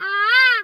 bird_peacock_squawk_soft_03.wav